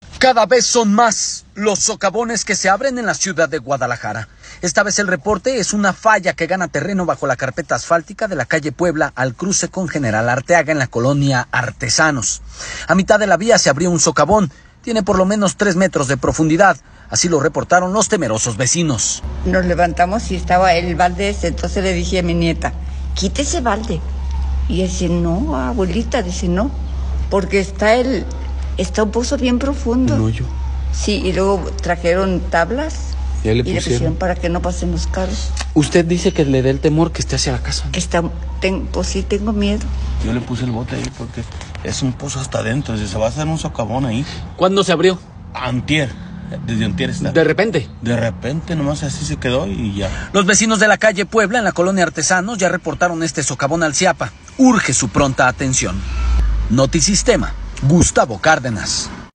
Esta vez el reporte es de una falla que gana terreno bajo la carpeta asfáltica de la calle Puebla al cruce con General Arteaga en la colonia Artesanos. A mitad de la vía se abrió un socavón, tiene por lo menos 3 metros de profundidad. Así lo reportaron los temerosos vecinos.